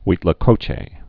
(wētlä-kōchā)